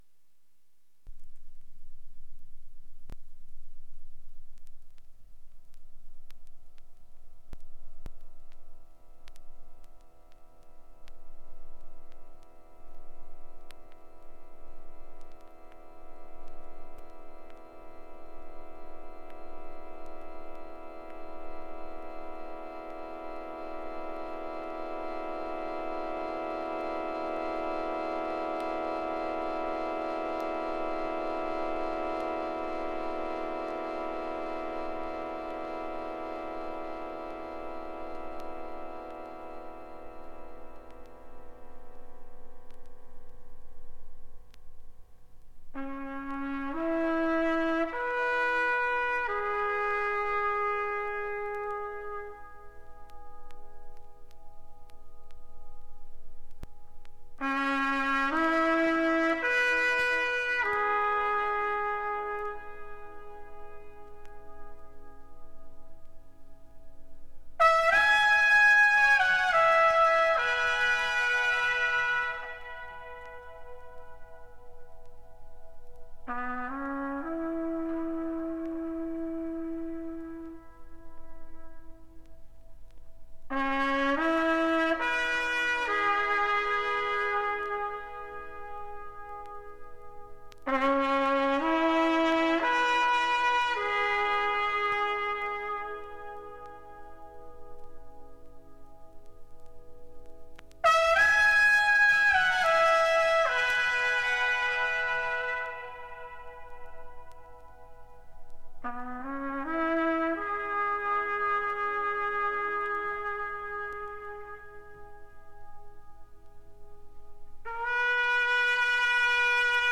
Un peu de free-jazz pour faire plaisir à papa